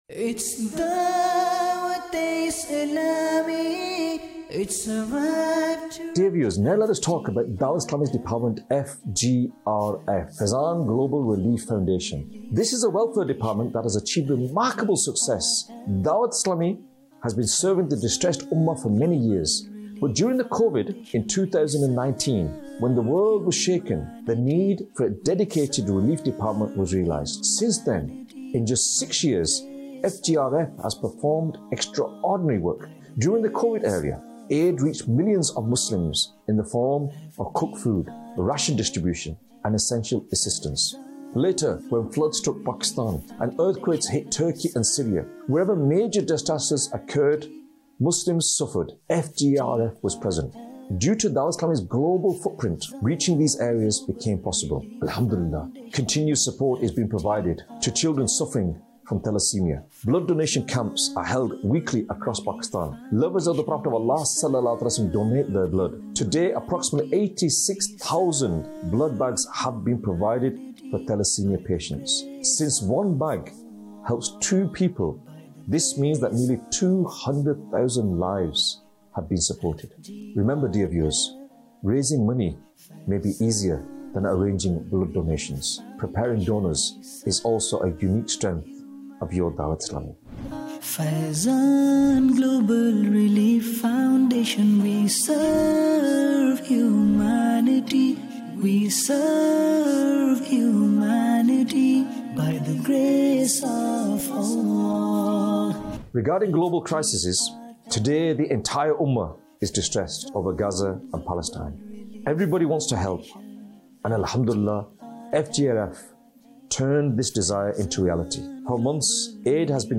Documentary 2026